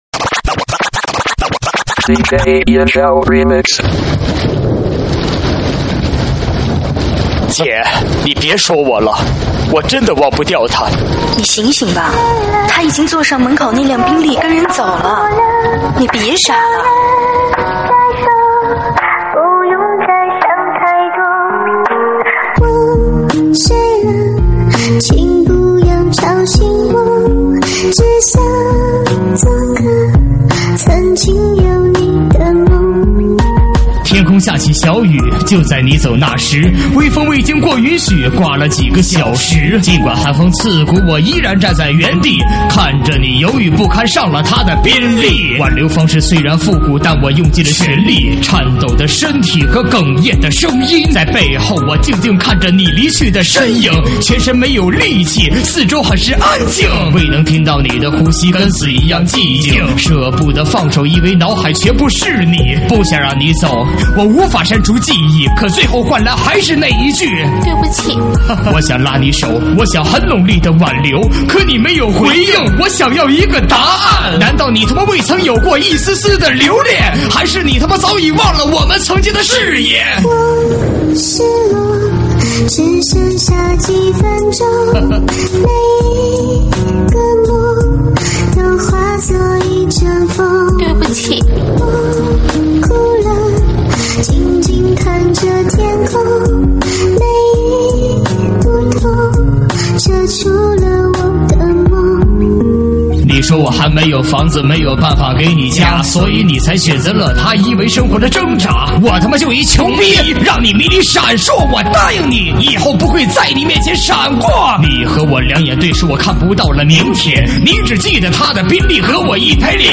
伤感情歌 暖场